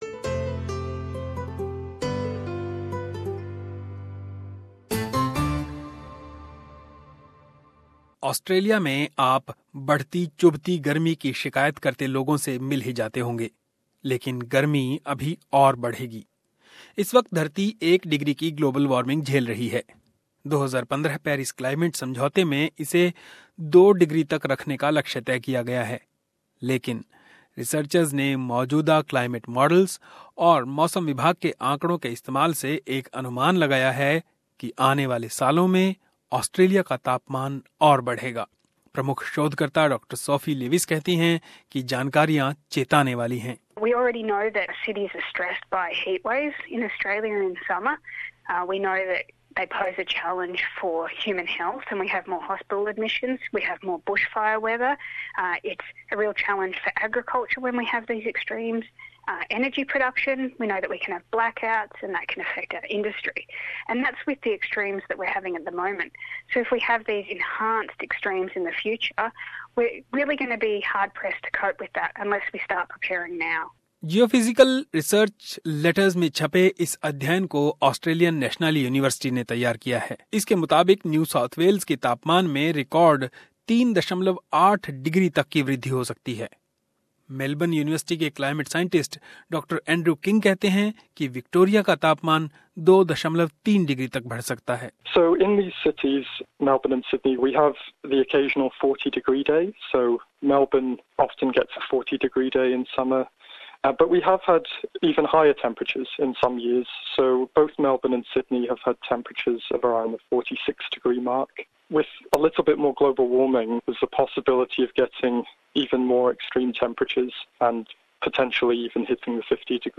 एक रिपोर्ट...